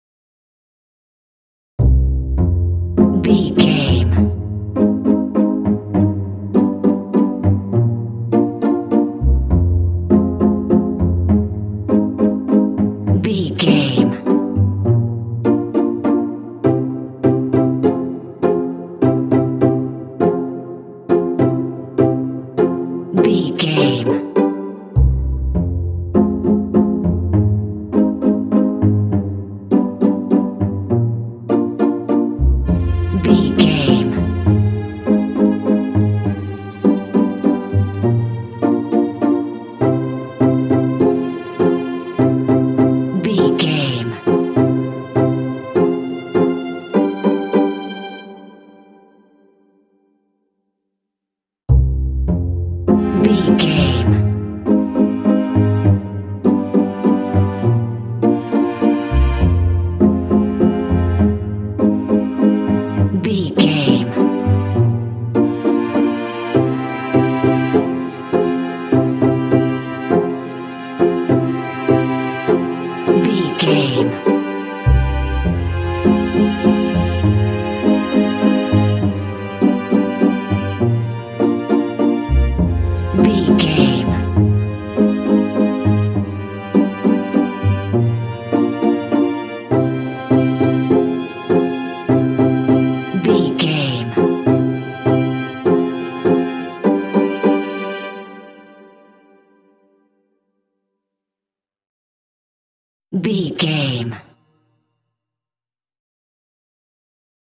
Aeolian/Minor
funny
playful
foreboding
suspense
double bass
contemporary underscore